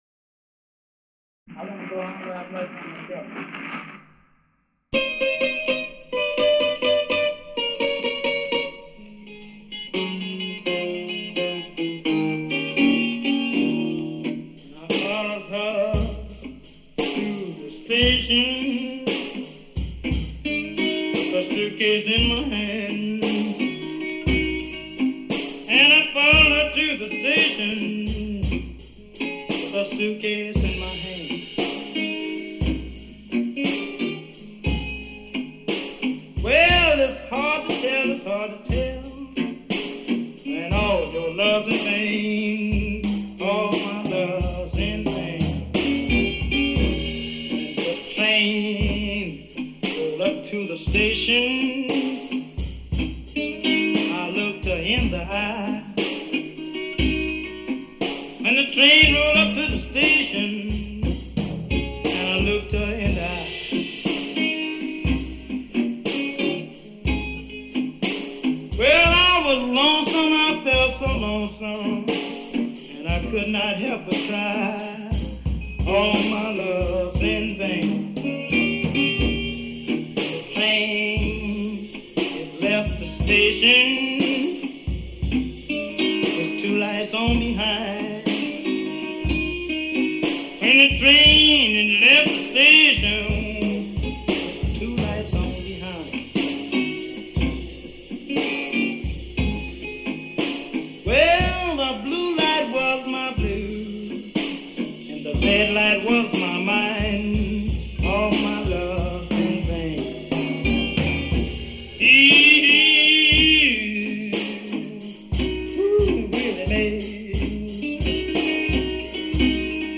drums, perc, ac-guitar, e-bass, keyboard
recorded with Lexicon Omega and Cubase5